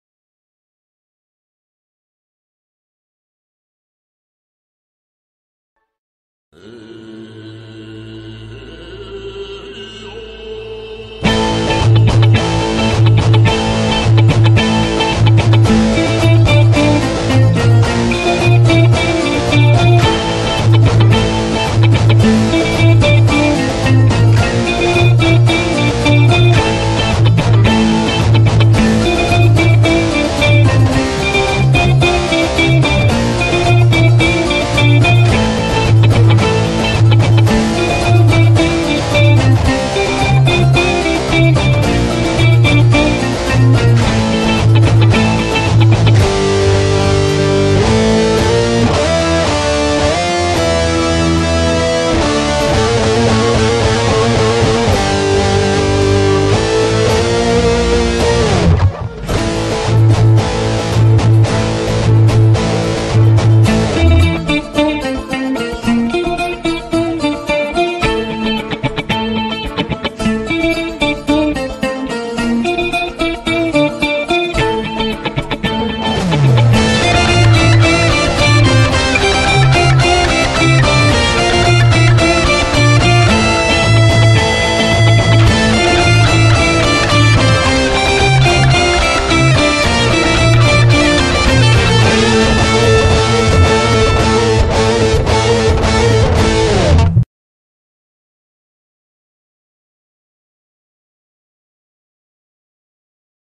Та же мелодия в элетронном исполнении в стиле рок